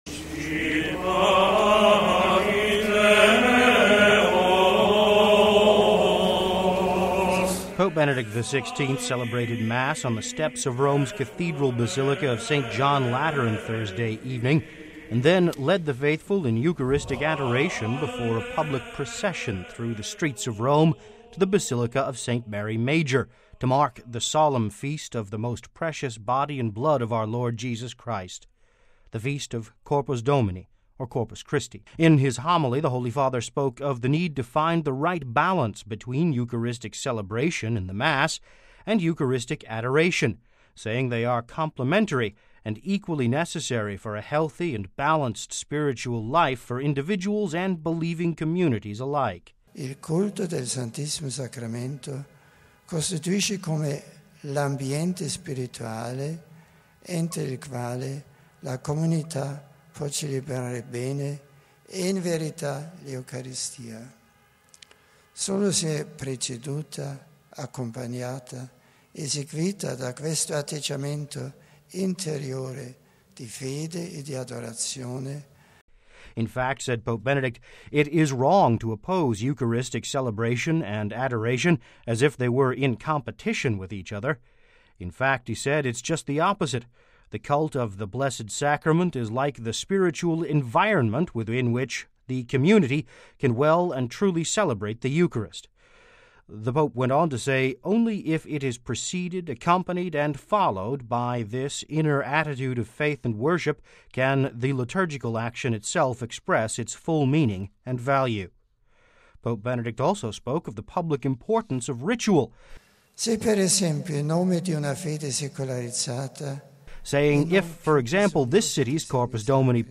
Pope Benedict XVI celebrated Mass on the steps of Rome’s Cathedral Basilica of St John Lateran Thursday evening, and then led the faithful in Eucharistic adoration before a public procession through the streets of Rome to the Basilica of St Mary Major, to mark the Solemn Feast of the Most Precious Body and Blood of Our Lord, Jesus Christ – the Feast of Corpus Domini or Corpus Christi.